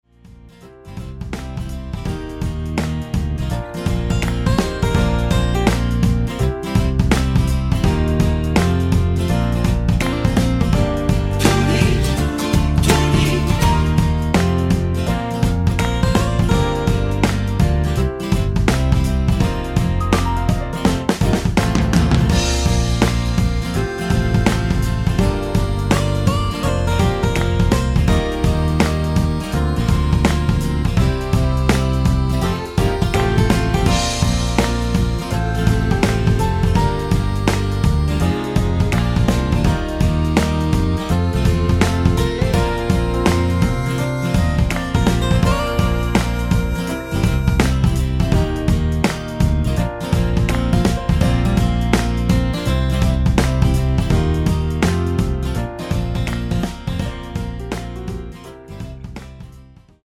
둘이~ 하는 부분 코러스 추가된 MR 입니다.(미리듣기 참조)
Bb
앞부분30초, 뒷부분30초씩 편집해서 올려 드리고 있습니다.